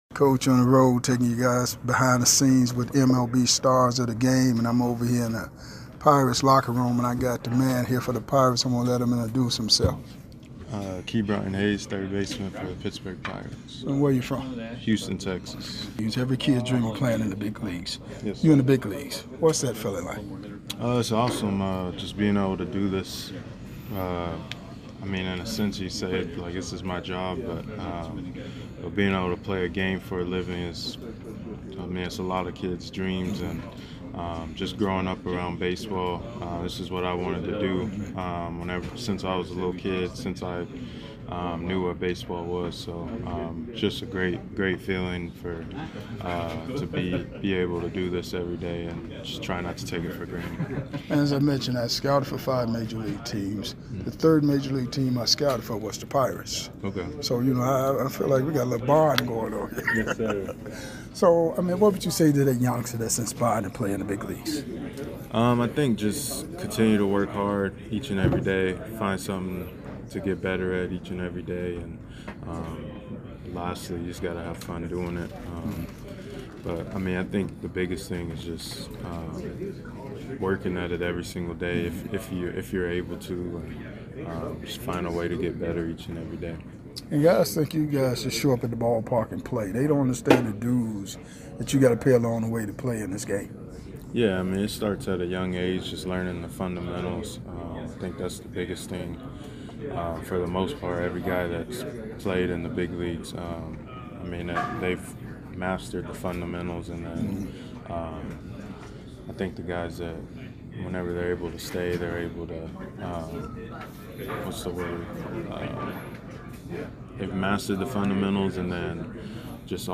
⚾ MLB Classic Interviews